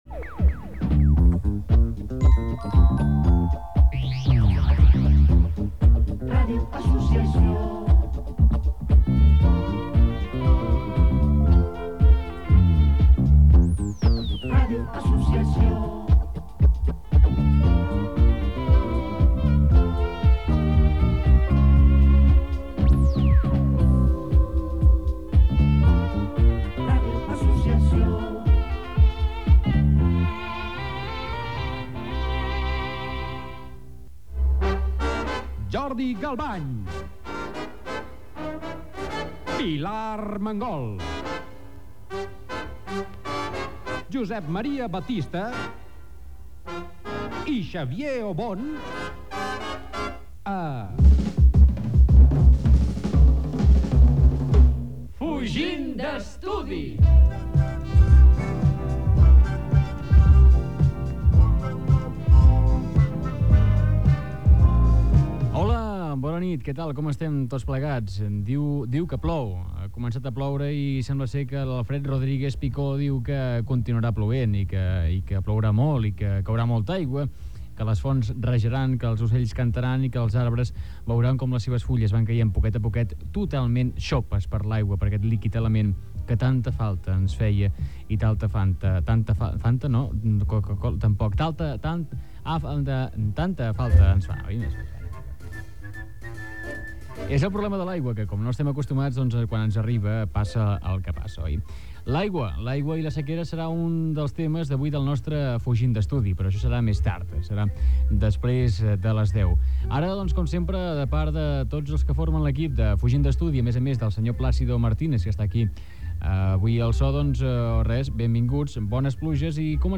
Indicatiu de l'emissora, careta del programa, comentari sobre la pluja, un oriental a Tossa, informació Gènere radiofònic Entreteniment